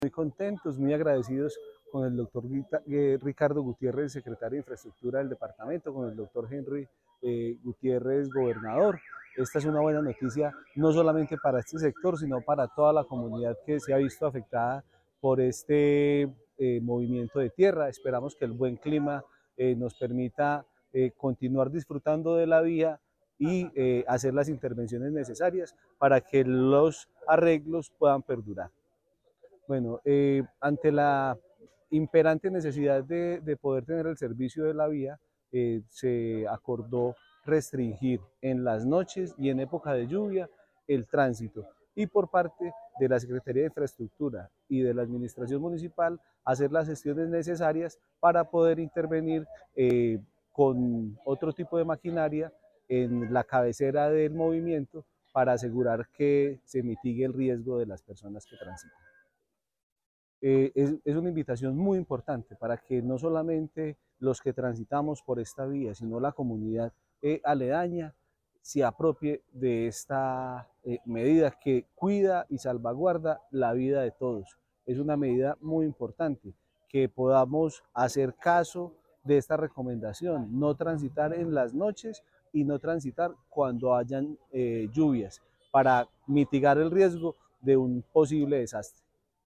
Alcalde de San José, Jorge Andrés Henao Castaño.